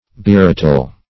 Search Result for " berattle" : The Collaborative International Dictionary of English v.0.48: Berattle \Be*rat"tle\ (b[-e]*r[a^]t"t'l), v. t. To make rattle; to scold vociferously; to cry down.